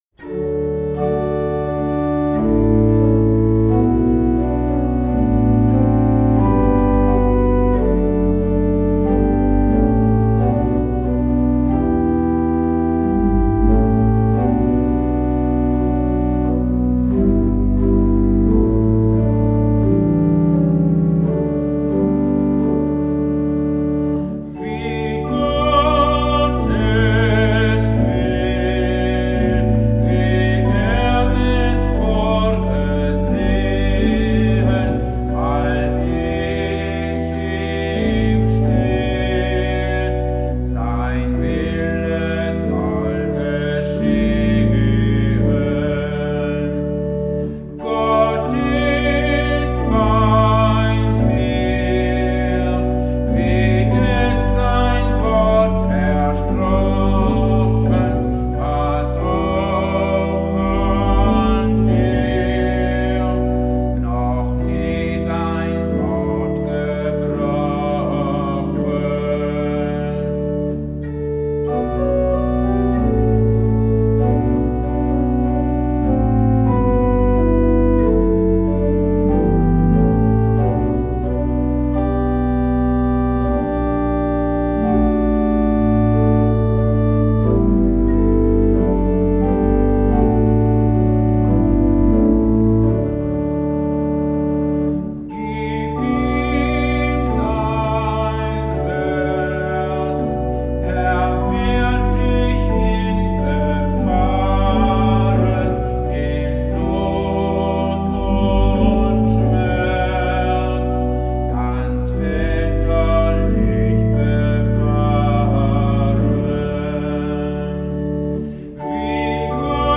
Hier finden Sie Aufnahmen meiner Orgel-Improvisationen über Choräle und Volkslieder.